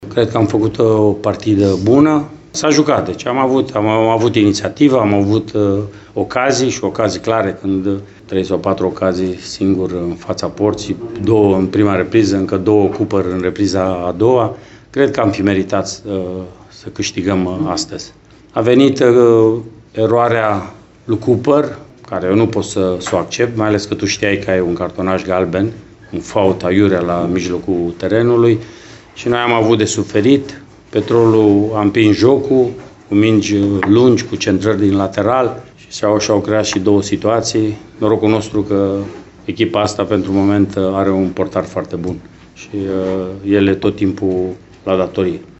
Antrenorul utist Mircea Rednic a avut aprecieri la adresa goalkeeper-ului său de azi: